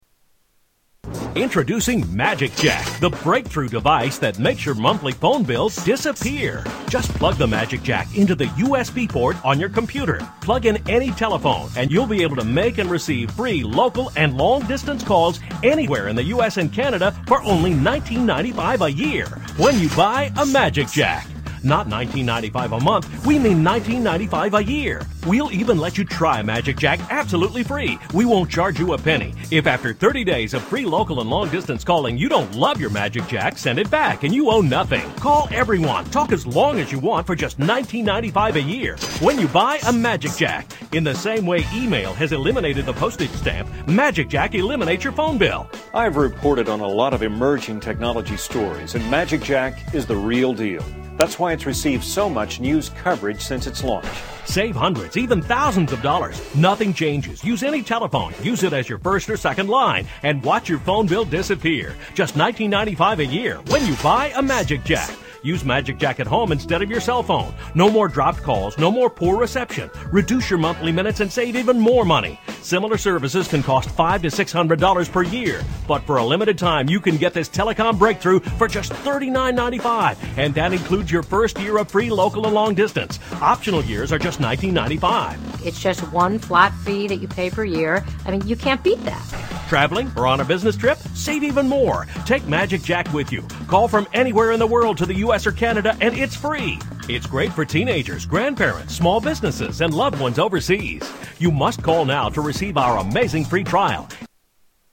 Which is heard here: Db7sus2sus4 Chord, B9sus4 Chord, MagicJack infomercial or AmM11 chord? MagicJack infomercial